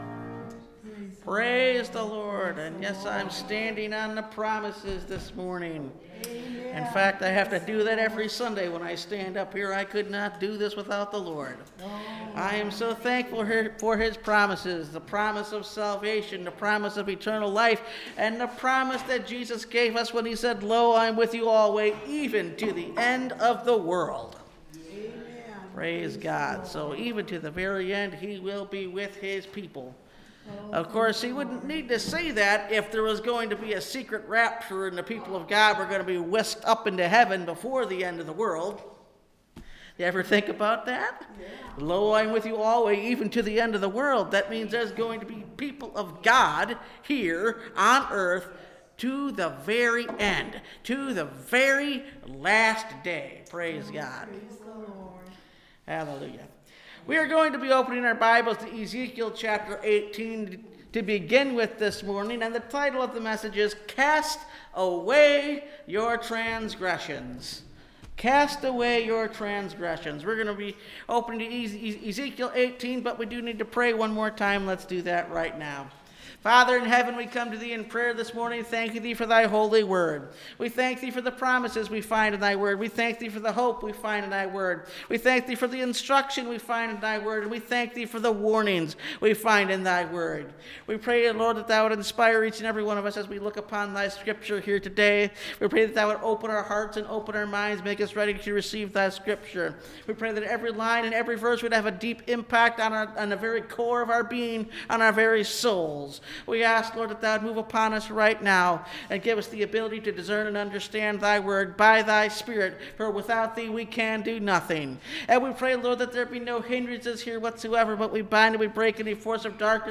Cast Away Your Transgressions (Message Audio) – Last Trumpet Ministries – Truth Tabernacle – Sermon Library